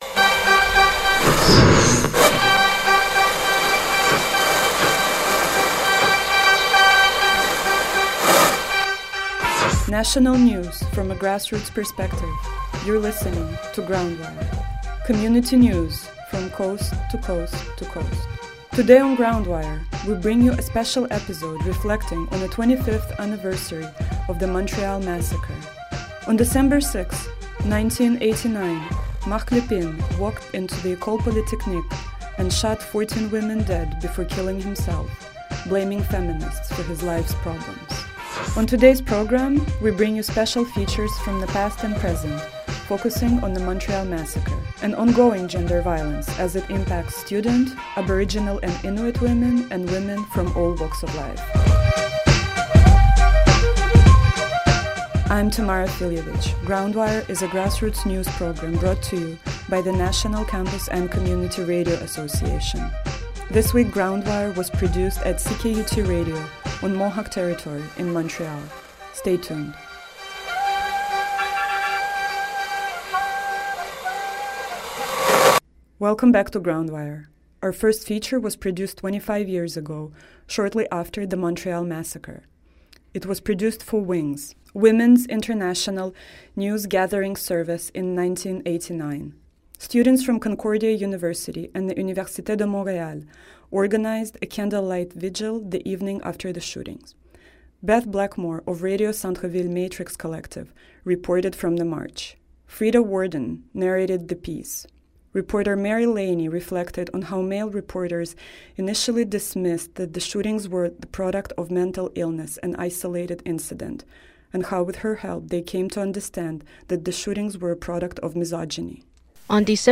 GroundWire: National Radio News